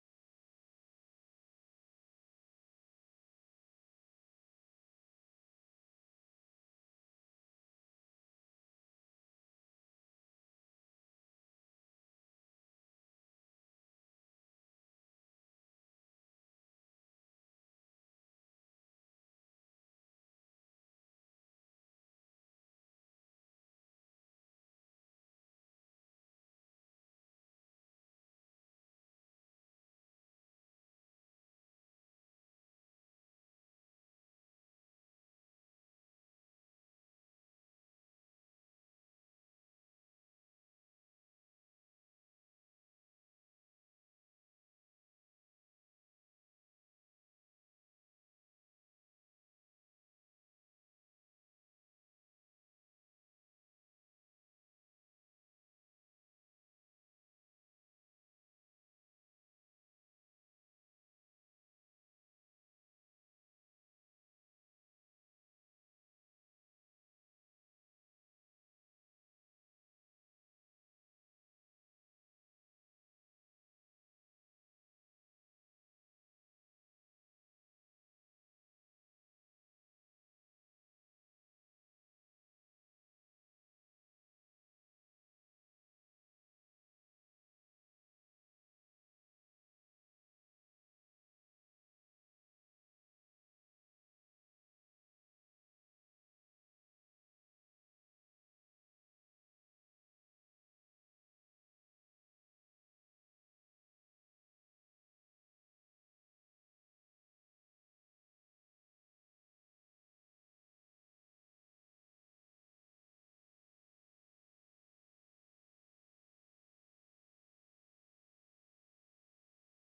Genre laisse
Répertoire de chansons populaires et traditionnelles
Pièce musicale inédite